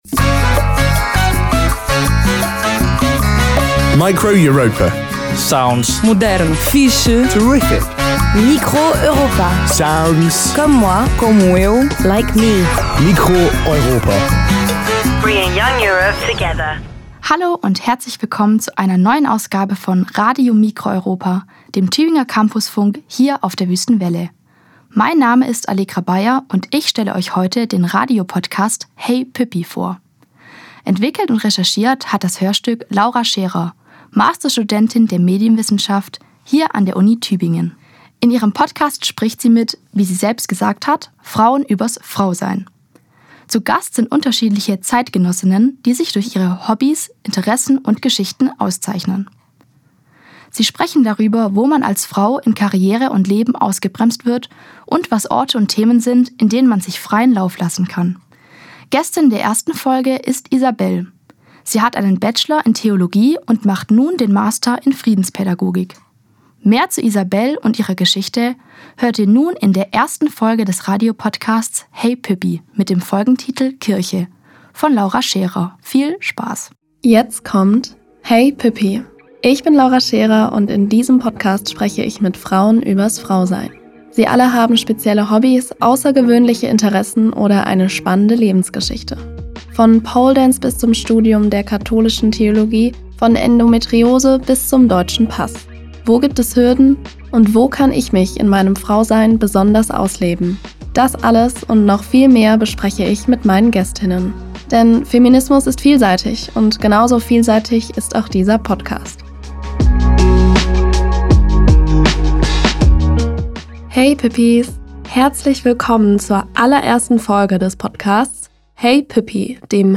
In jeder der acht Folgen berichtet eine andere Frau über ihre Lebensgeschichte, ihr Hobby oder ihrem Beruf und worauf es dabei ankommt.
Form: Live-Aufzeichnung, geschnitten